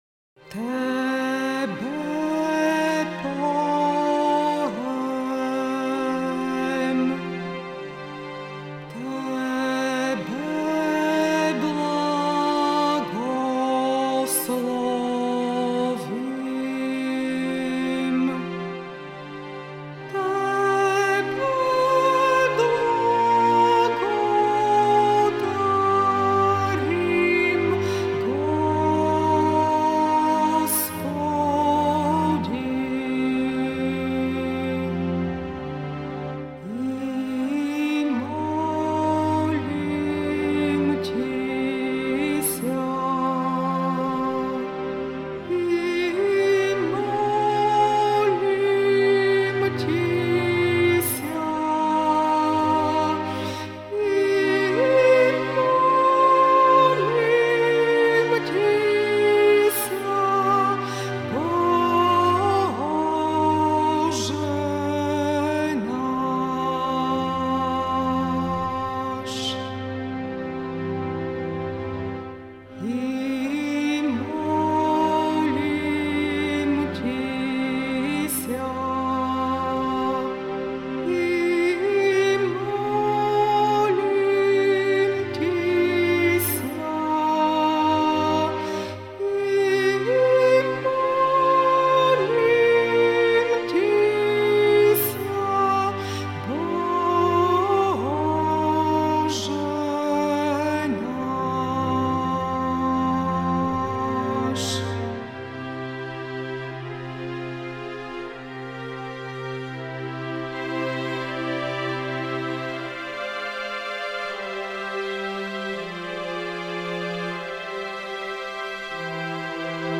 Questo canto fa parte del repertorio ecclesiastico bulgaro ed era diventato popolare all’interno della Fratellanza Bianca.
Tebe poem – Per Te cantiamo Spartito non ancora disponibile (Versione strumentale non ancora disponibile) Versione vocale Tebe Poem Testo traslitterato Tebe poem Tebe poem, Tebe blagodarim.